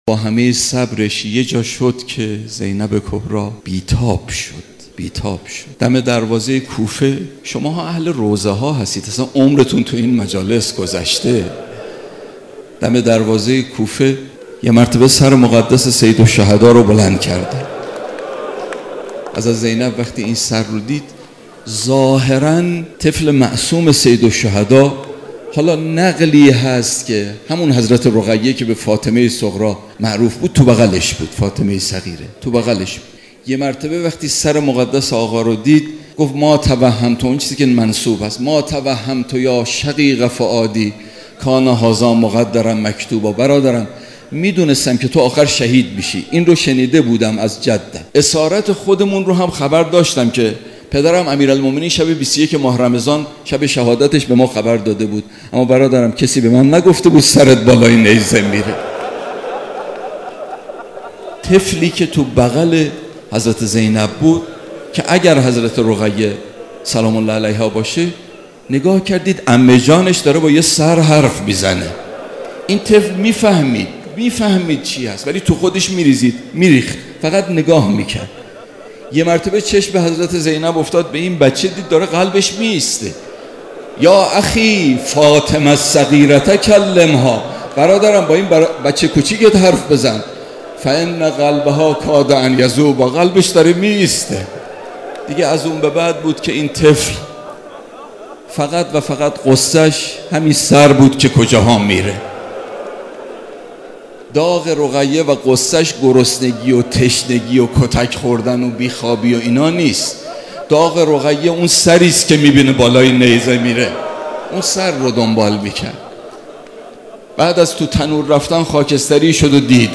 روضه حضرت رقیه «سلام الله علیها»